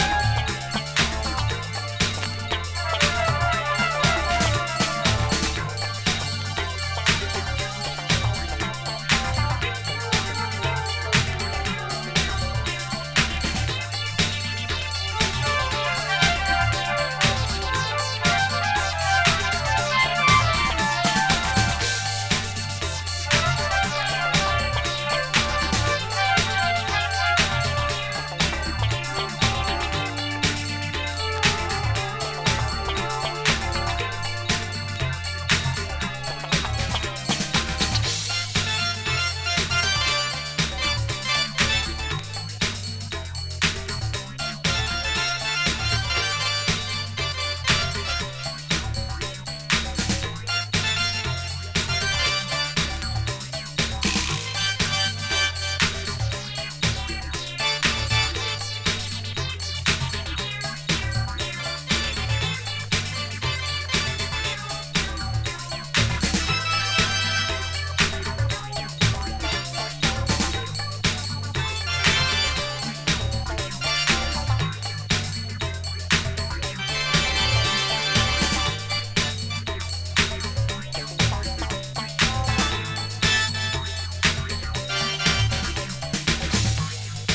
浮遊するキーボードにグルーヴィンなベースラインがたまらない
極上Discoサウンドが詰まってます。